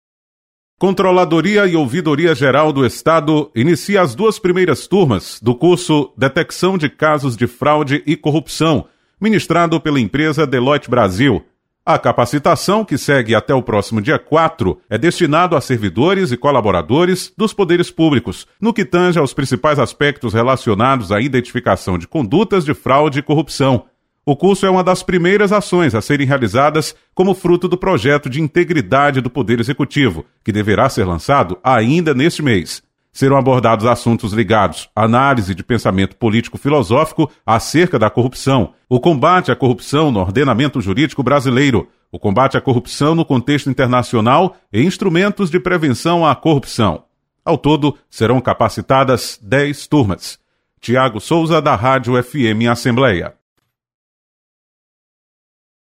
CGE realiza curso para combate a fraude e corrupção. Repórter